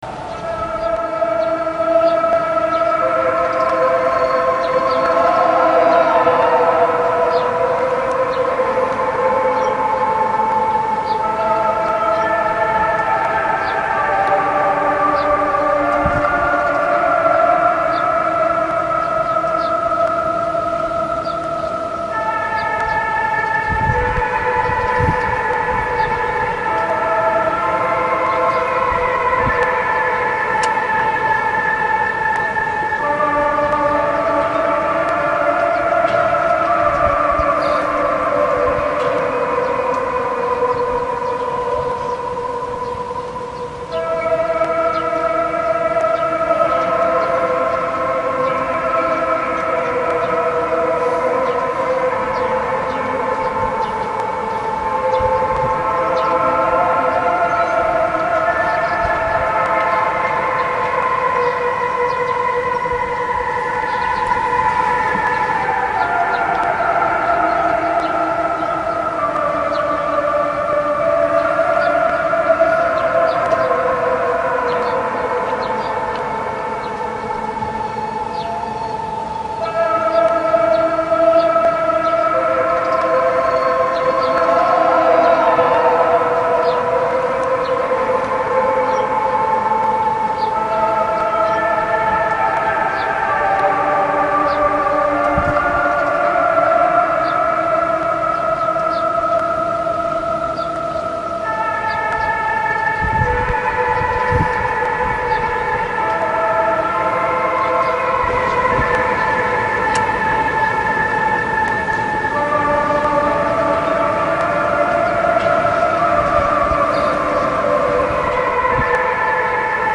По вечерам в Пхеньяне по громкоговорителям проигрывают эту красивую мелодию...Очень печальную и очень знакомую...Интересно, что это за мелодия?
Запись конечно не очень , но мне сначала показалось, что это песня "Ревёт и стонет Днепр широкий"